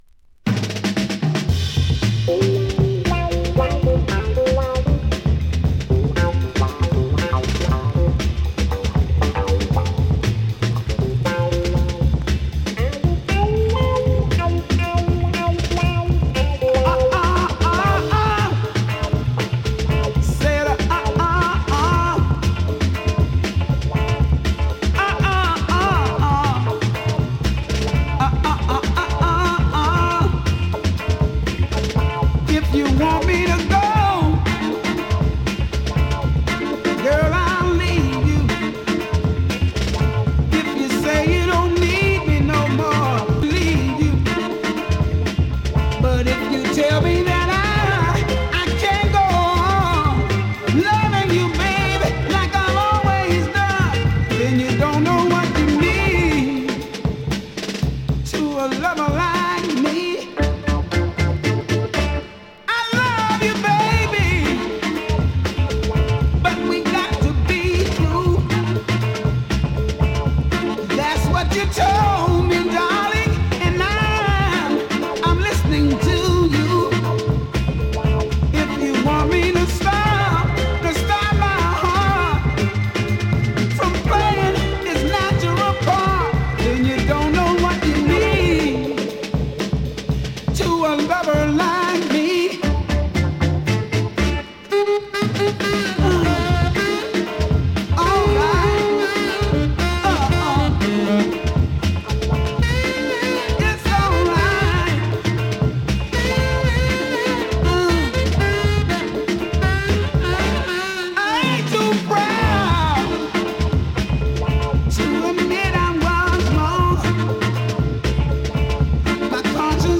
現物の試聴（両面すべて録音時間７分）できます。